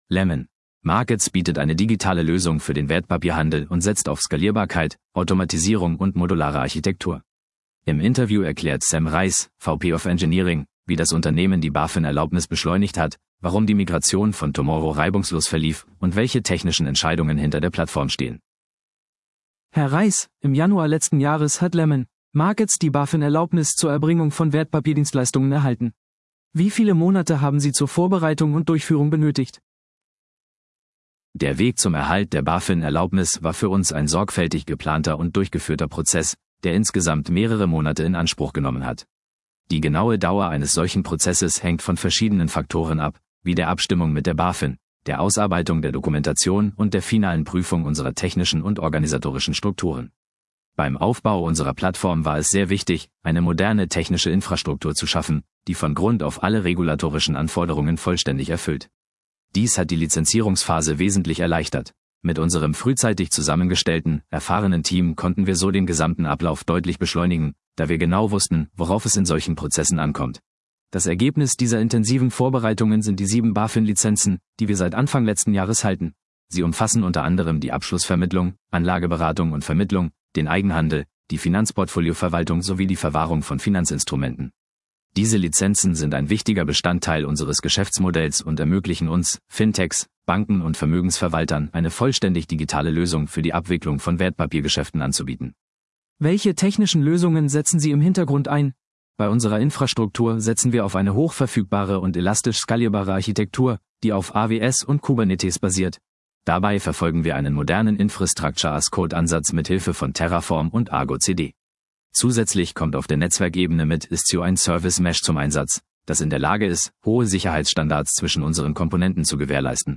VP of Engineering im IT‑Interview